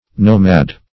Nomad \Nom"ad\, a.